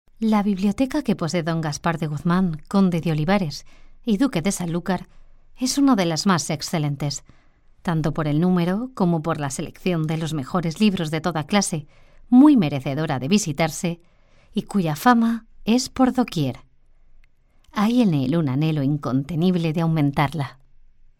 locutora, spanish voice over